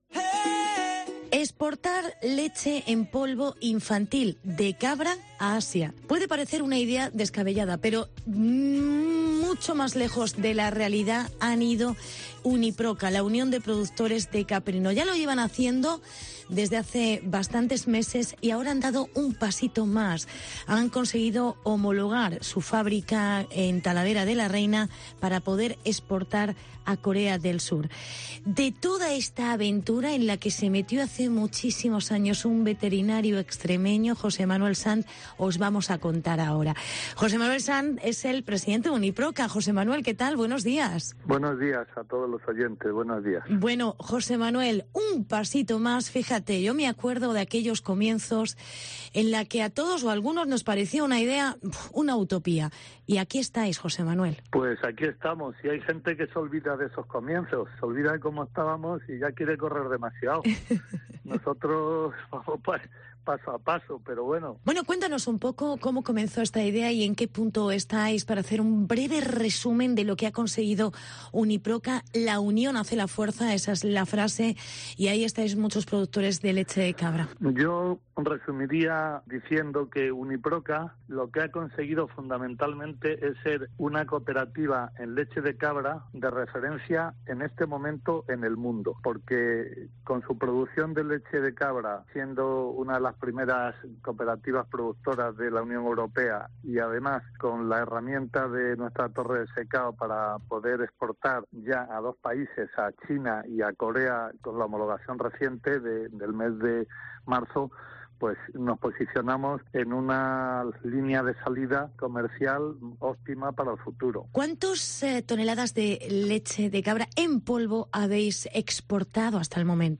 AUDIO: Uniproca exportará su leche de cabra en polvo infantil a Corea del Sur. Entrevista